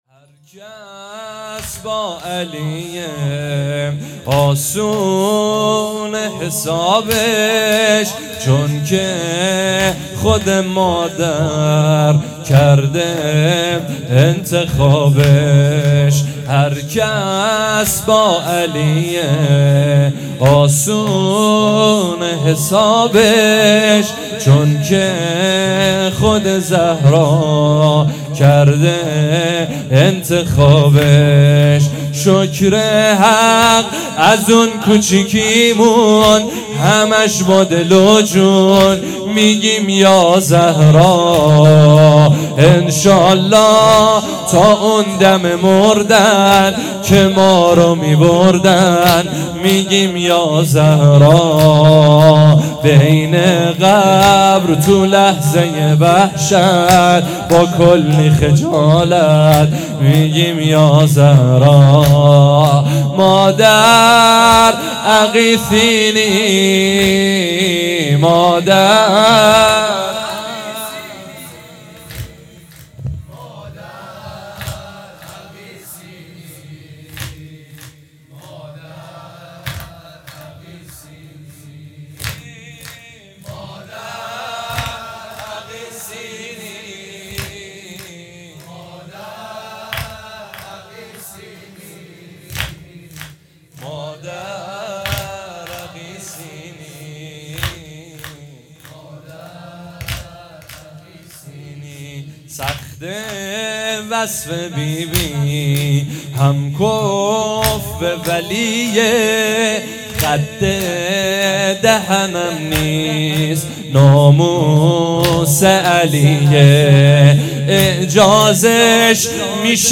هر کس با علیه حسابش اسونه _ واحد
اقامه عزای شهادت حضرت زهرا سلام الله علیها _ دهه اول فاطمیه